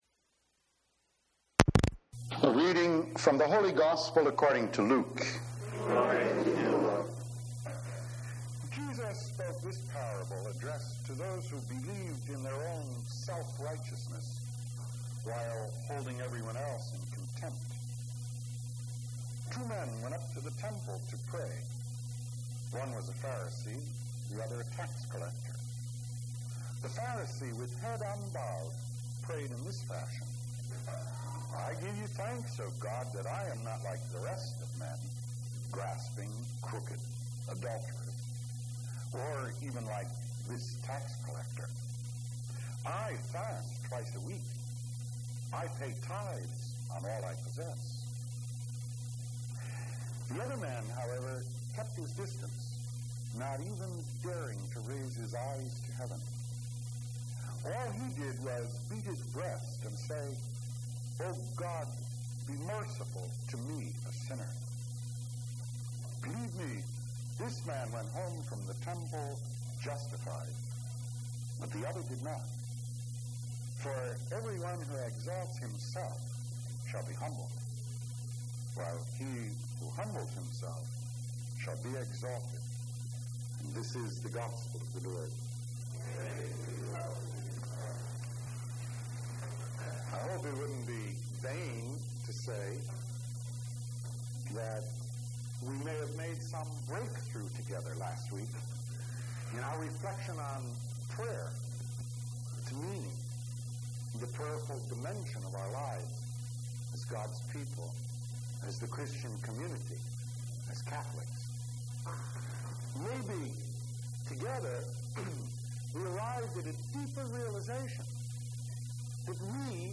Today we hear the homily on the Prodigal Son.